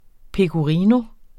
Udtale [ pekoˈʁiːno ]